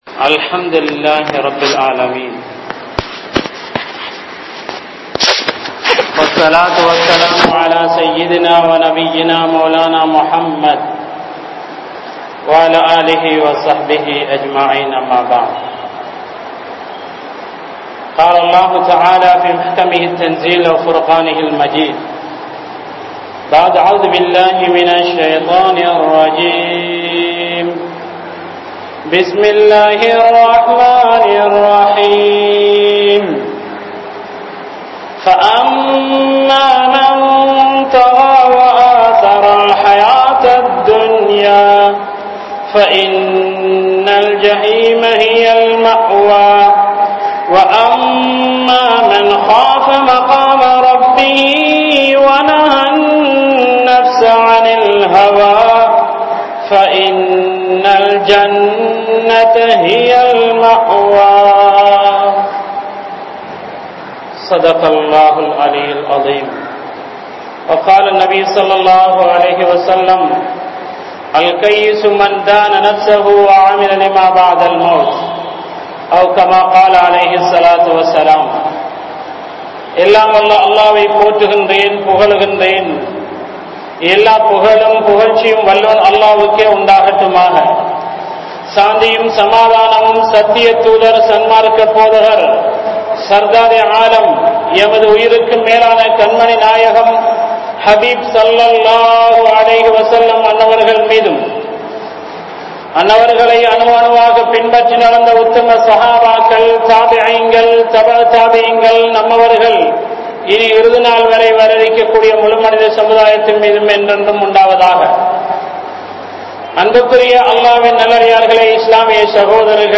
Marumai Naalil Manithanin Nilamai(The situation of man in the Hereafter) | Audio Bayans | All Ceylon Muslim Youth Community | Addalaichenai
Thaqwa Jumua Masjith